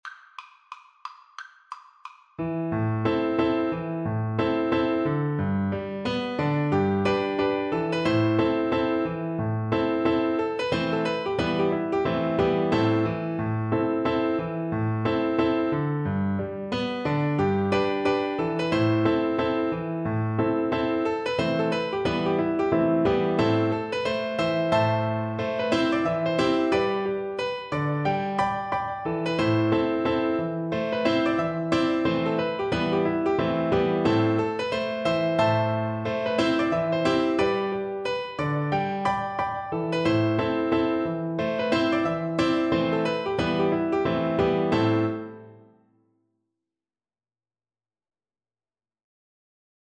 Flute
Traditional Music of unknown author.
G major (Sounding Pitch) (View more G major Music for Flute )
4/4 (View more 4/4 Music)
Presto =c.180 (View more music marked Presto)